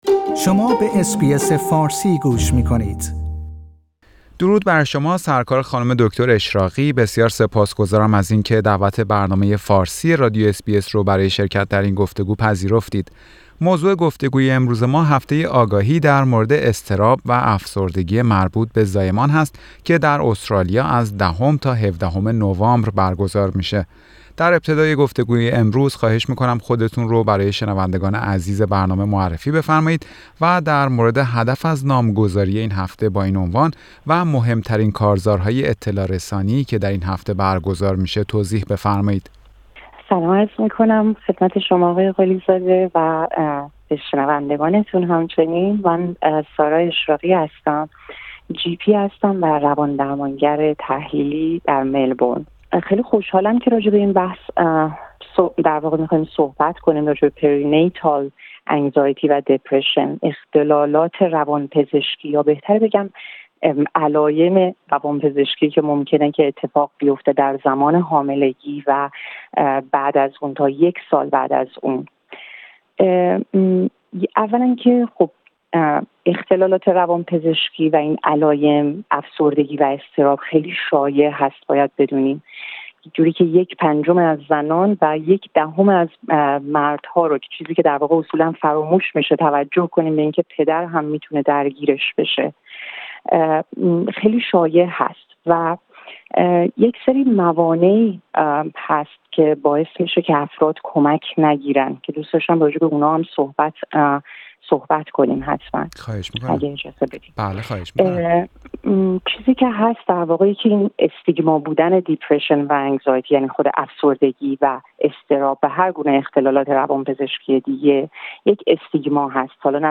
برنامه فارسی رادیو اس بی اس در همین خصوص گفتگویی داشته